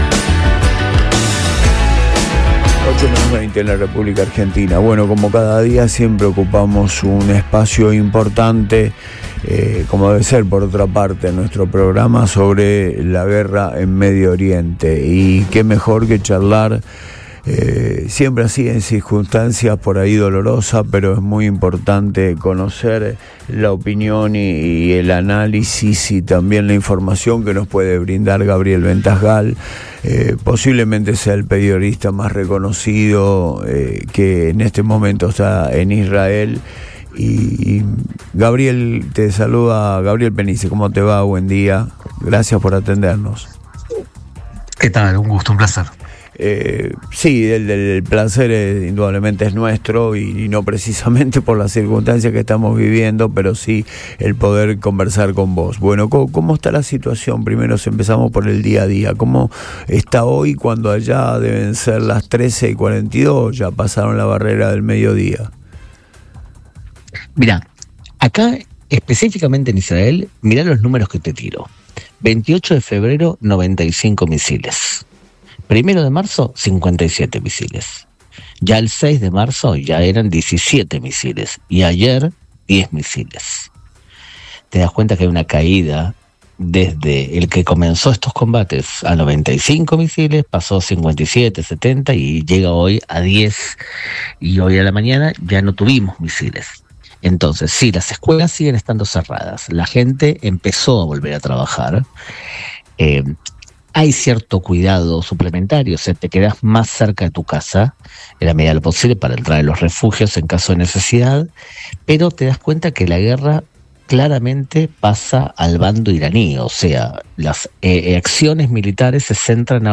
En diálogo en el programa Antes de Todo de Radio Boing